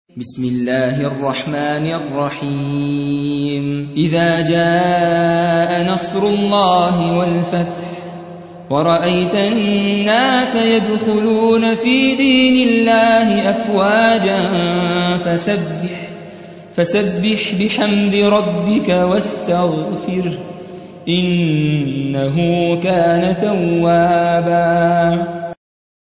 110. سورة النصر / القارئ